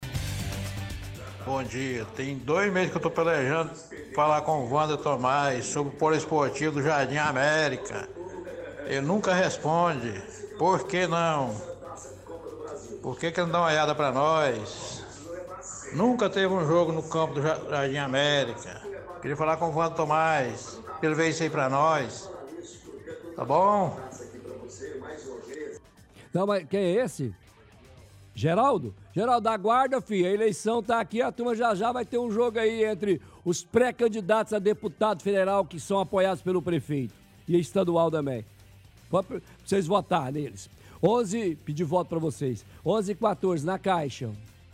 – Ouvinte reclama que não tem jogos no poliesportivo do Jardim América.